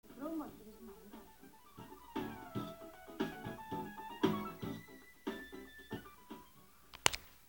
키보드 솔로
폰으로 녹음했는데 녹음 시작하자마자 페이드인 되더군요..
소리가 많이 작아서 높게 볼륨업 해야 들릴겁니다